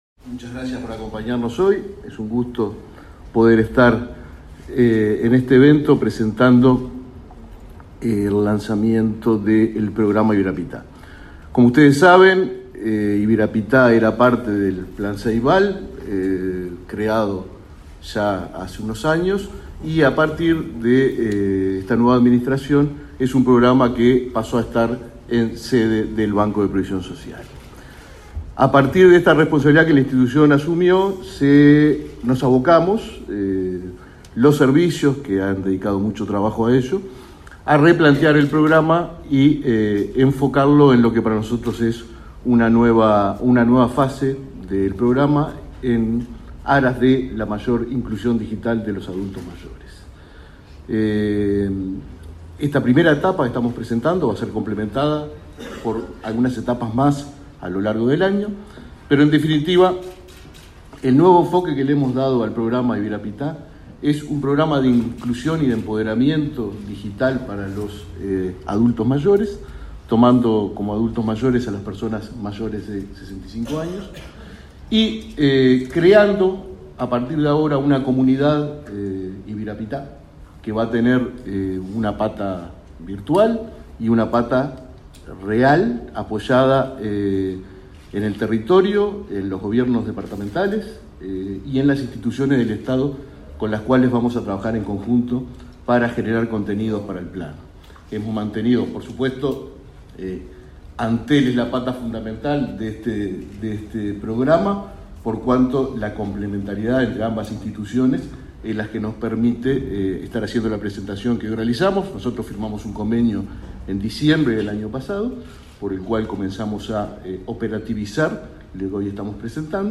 Palabras de autoridades en lanzamiento del programa Ibirapitá del BPS
Palabras de autoridades en lanzamiento del programa Ibirapitá del BPS 26/07/2022 Compartir Facebook X Copiar enlace WhatsApp LinkedIn El presidente del Banco de Previsión Social (BPS), Alfredo Cabrera, y el titular de Antel, Gabriel Gurméndez, participaron en el relanzamiento del programa Ibirapitá, que pasó a la órbita del organismo previsional.